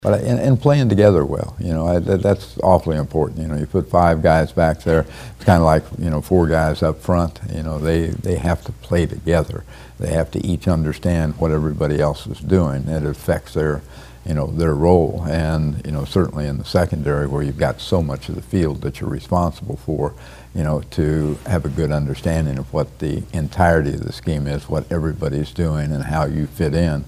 Here is K-State head coach Bill Snyder talking about his secondary.